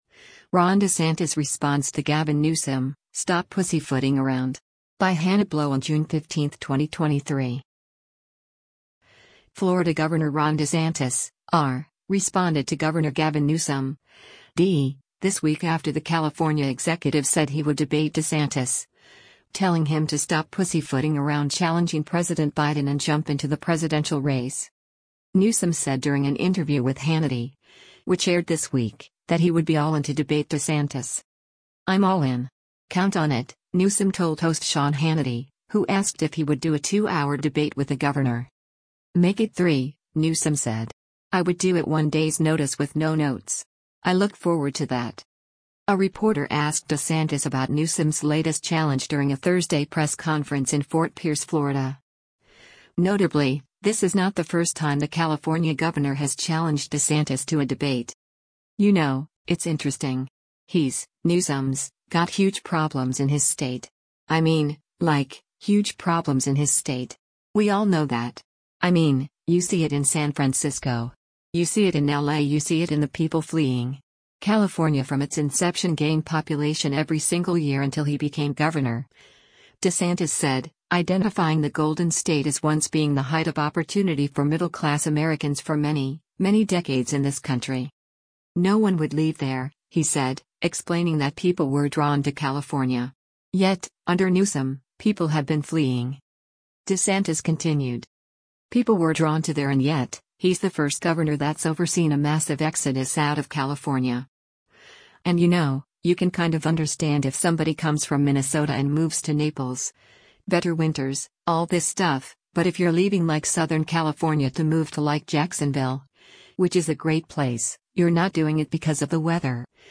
A reporter asked DeSantis about Newsom’s latest challenge during a Thursday press conference in Fort Pierce, Florida.
“What I would tell him is, you know what? Stop pussyfooting around,” DeSantis said to applause.